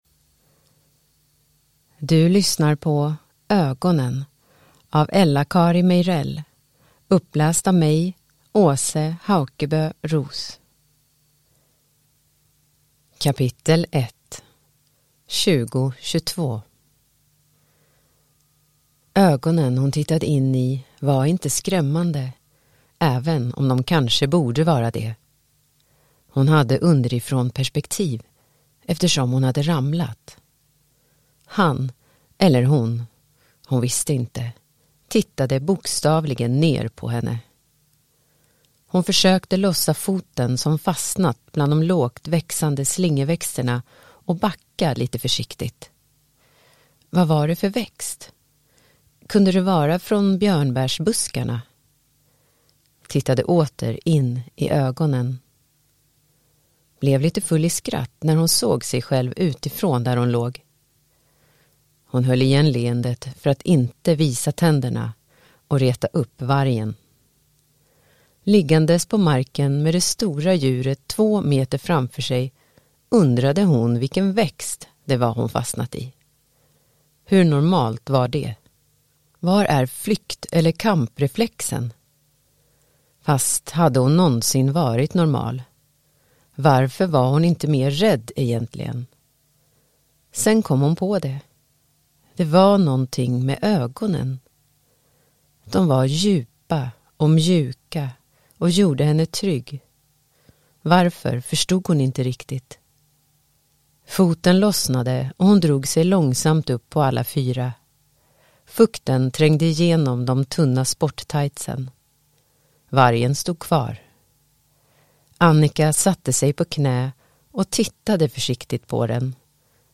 Ögonen – Ljudbok